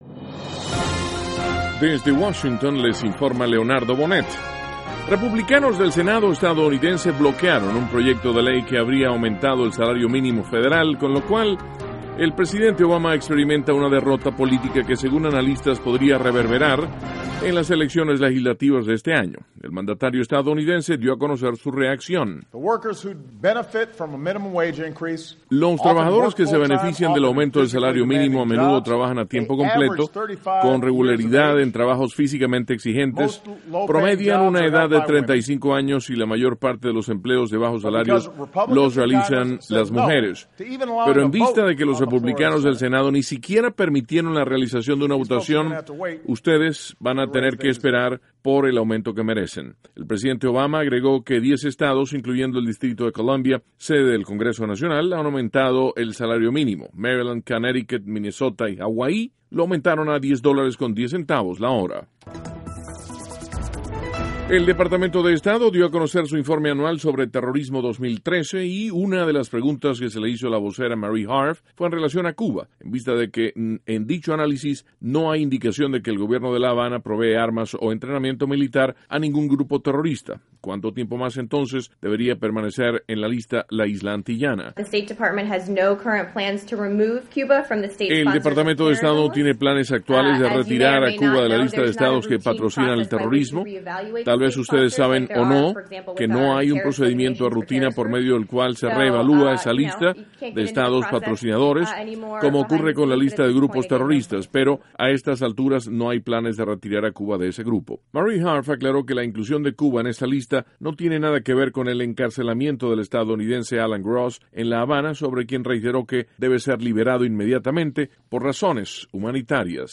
NOTICIAS - MIÉRCOLES, 30 DE ABRIL, 2014
(Sonido Obama) 2.- El Departamento de Estado no tiene intención de retirar a Cuba de la lista de Estados que patrocinan el terrorismo. (Sonido Jen Psaki) 3.- Clima severo afecta la Península de Florida. (Sonido – Gobernador Rick Scott)